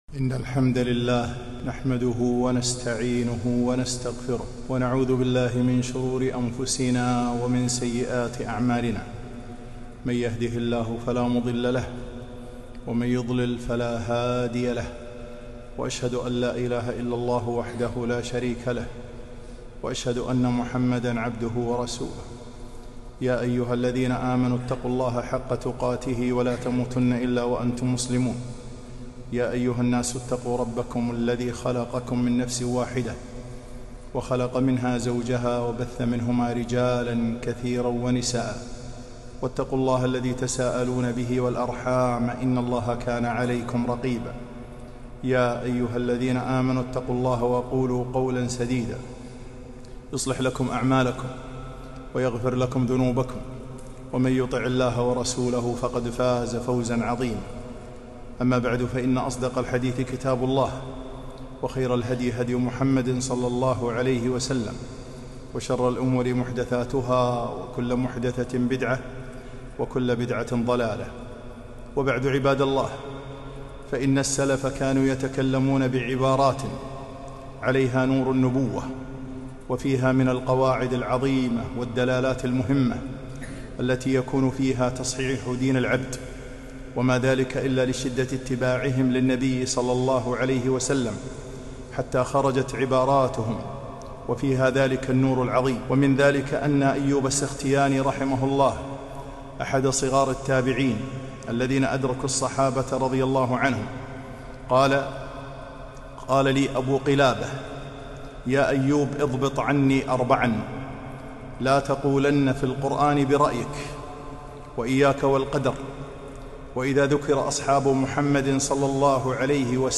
خطبة - مهمات في العقيدة